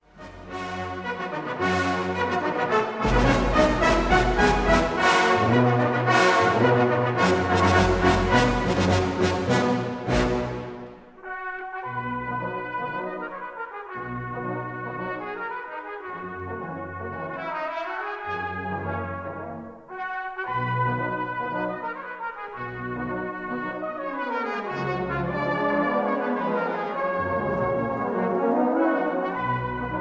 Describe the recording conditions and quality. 1959 stereo recording